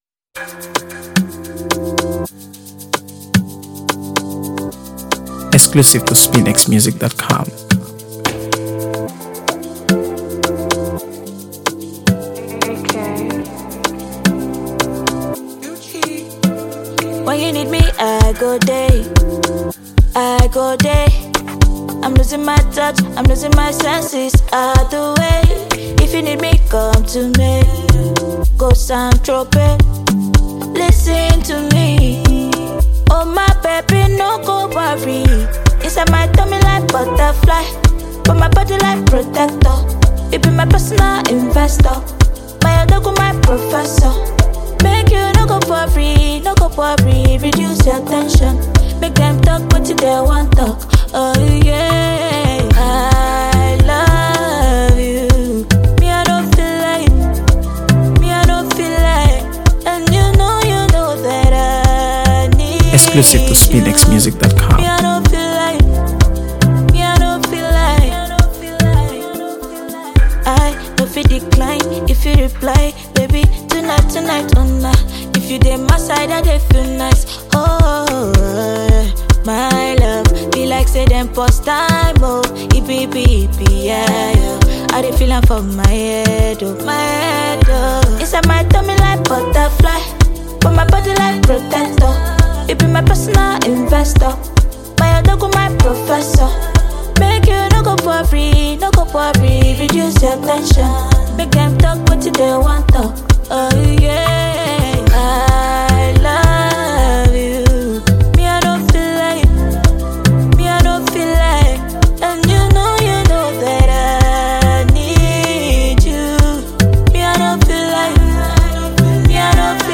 AfroBeats | AfroBeats songs
blends smooth Afrobeats rhythms with a pop-infused melody